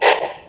step.wav